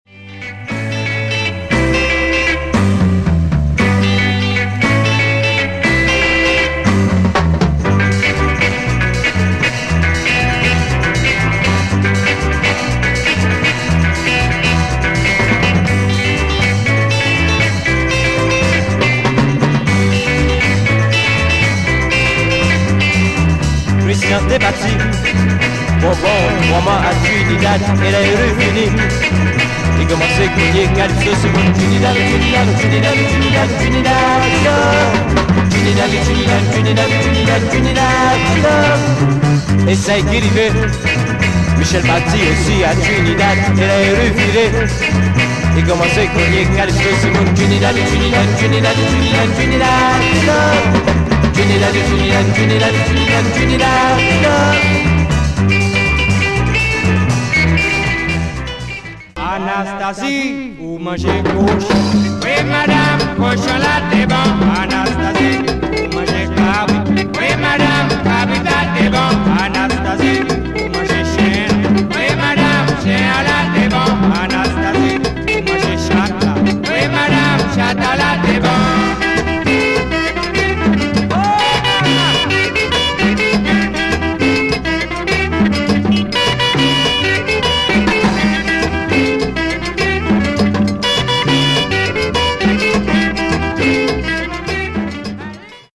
HomeWorld MusicLatin  >  Salsa / Pachanga / Mambo / …